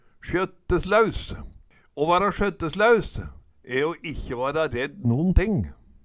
DIALEKTORD PÅ NORMERT NORSK sjøtteslæus uredd Eksempel på bruk Å vara sjøtteslæus